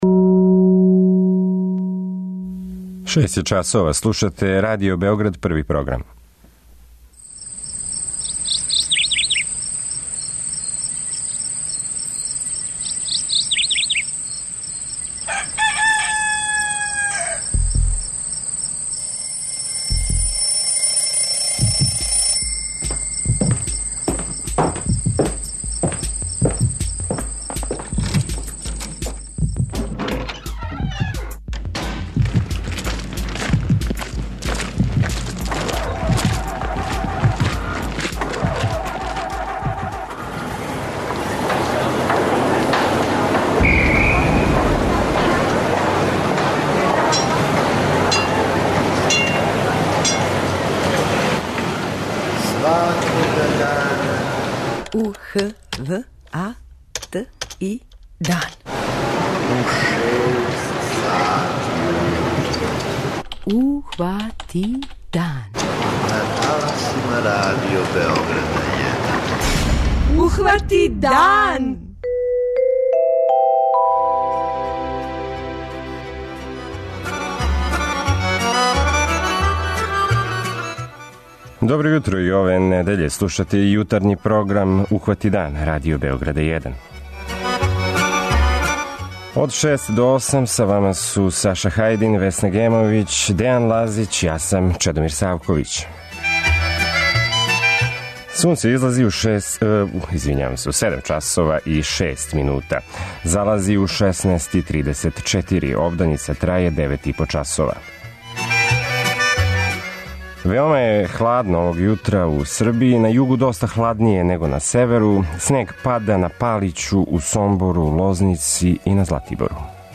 преузми : 57.31 MB Ухвати дан Autor: Група аутора Јутарњи програм Радио Београда 1!